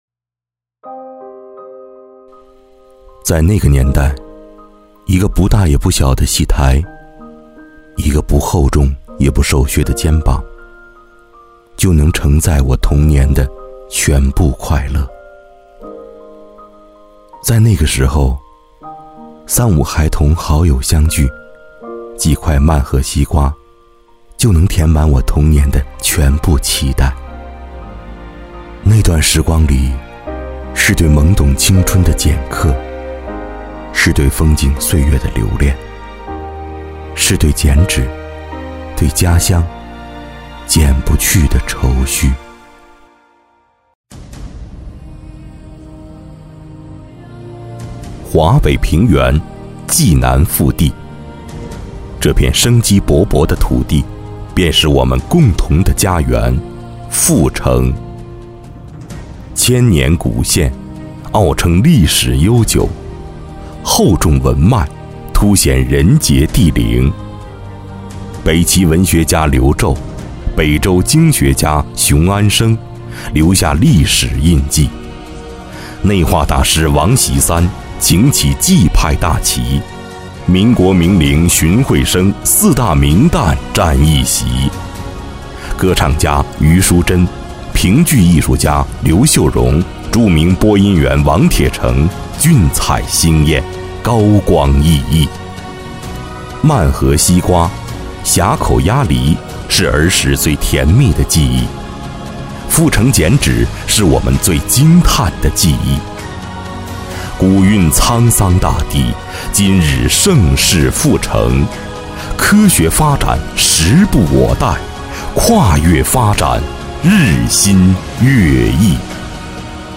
微电影配音
男国463_其他_微电影_自然独白走心.mp3